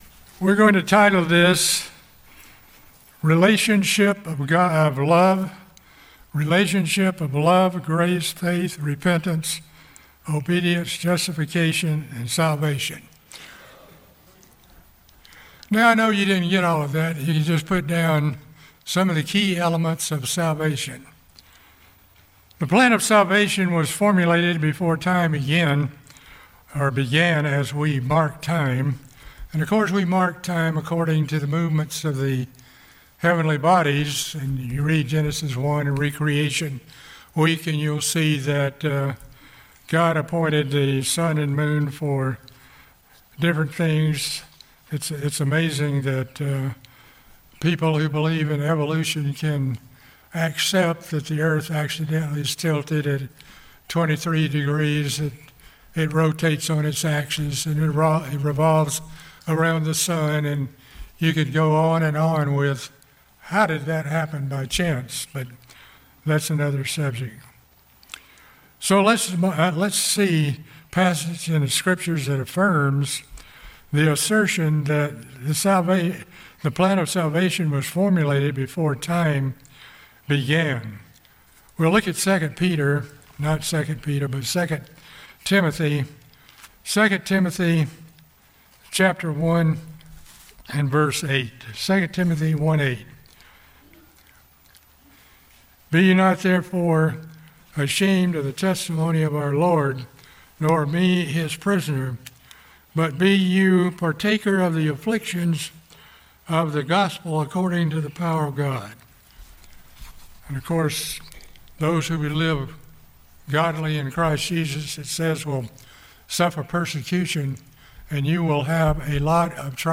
This sermon explores some of the necessary elements of the salvation process and the correct interpretation of some difficult scriptures.